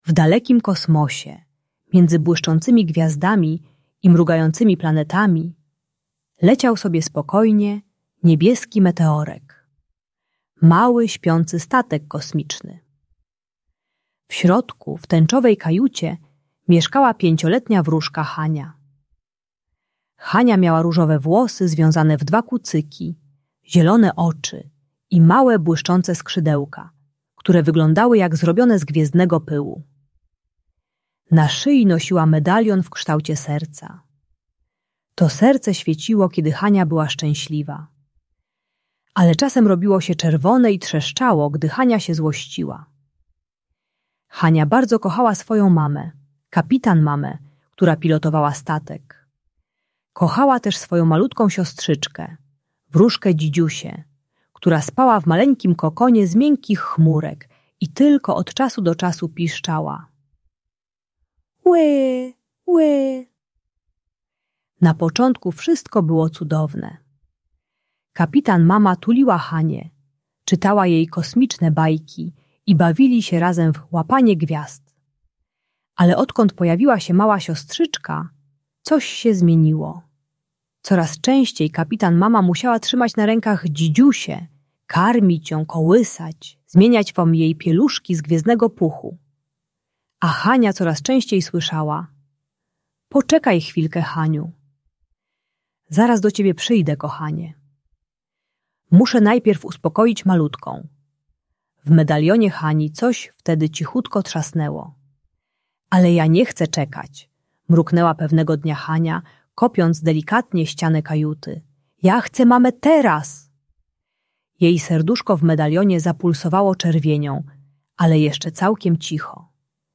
Audiobajka o zazdrości o rodzeństwo uczy techniki głębokiego oddychania (Tarcza Spokojnego Oddechu) - 3 wdechy i wydechy, by opanować złość zamiast krzyczeć i rzucać rzeczami.